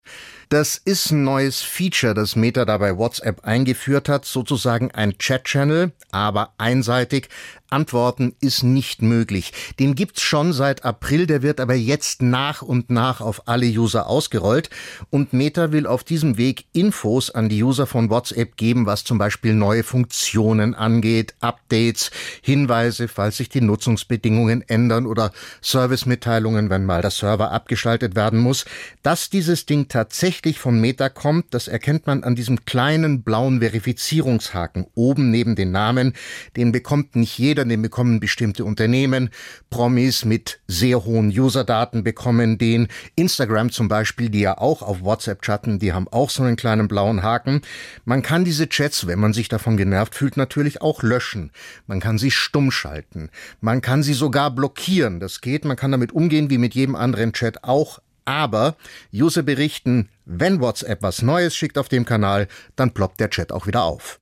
Hören statt lesen?